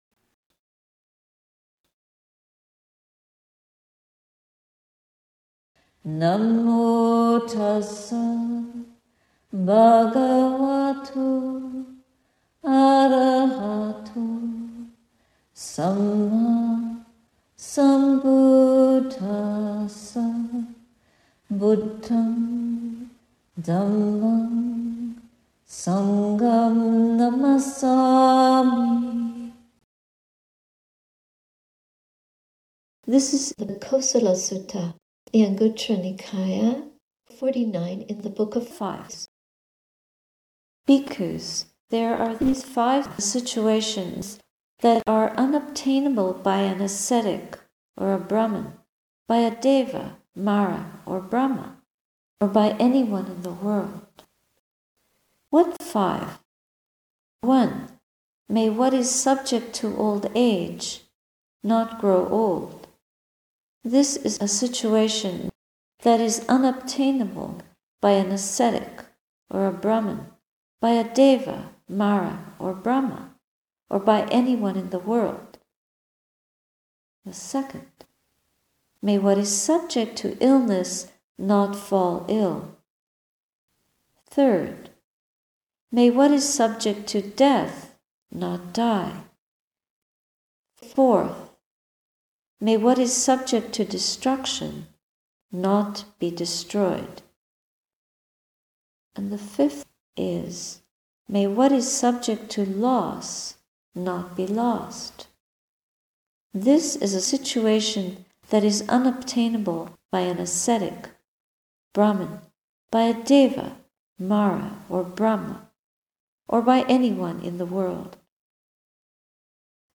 A Sati Saraniya Sunday talk, Oct. 15, 2017. https